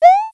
sound_player_jump.wav